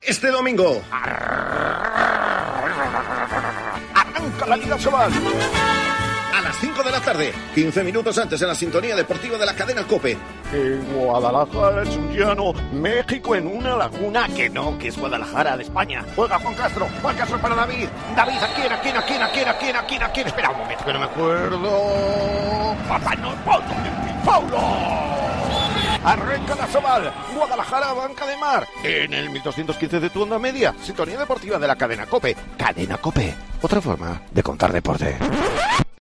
Escucha la cuña promocional del partido Guadalajara - Ademar el día 04-09-22 a las 17:00 h en el 1.215 OM